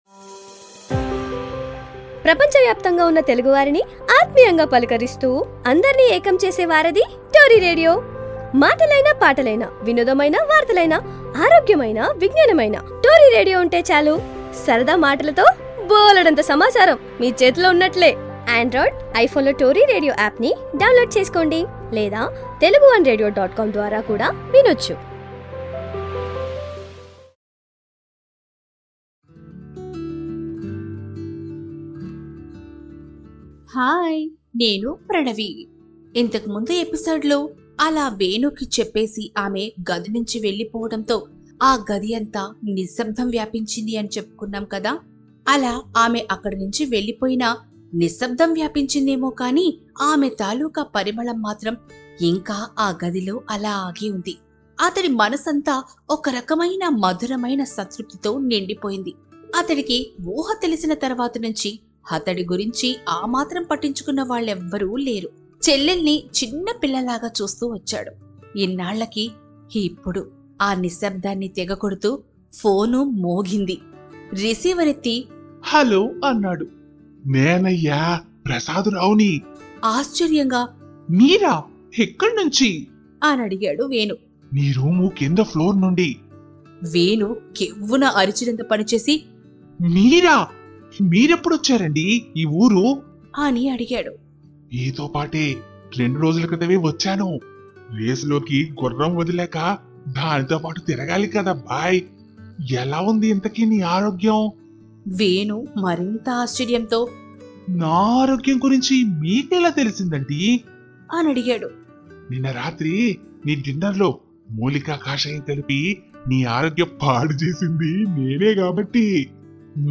Ep: 23. Sampoorna Premayanam | Yandamoori Veerendranath | Telugu Audio Book – Yandamoori Veerendranath - Sampoorna Premayanam (Telugu audio book) – Lyssna här – Podtail